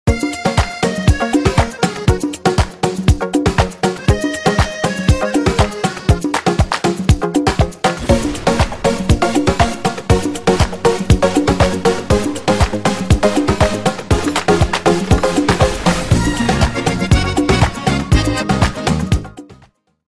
accordion music